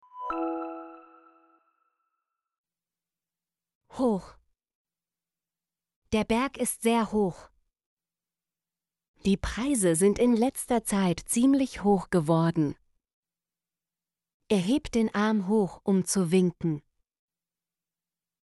hoch - Example Sentences & Pronunciation, German Frequency List